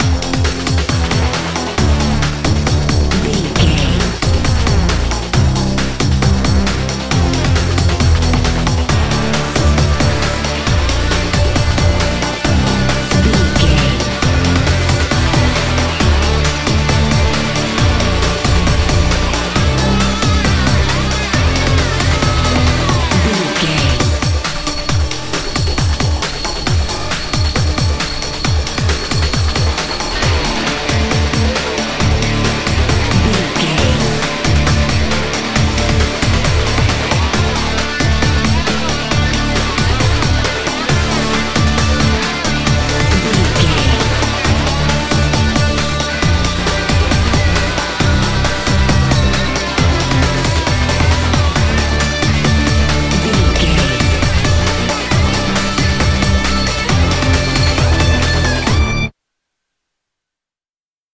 Aeolian/Minor
WHAT’S THE TEMPO OF THE CLIP?
drum machine
synthesiser
Sports Rock
hard rock
lead guitar
bass
drums
aggressive
energetic
intense
nu metal
alternative metal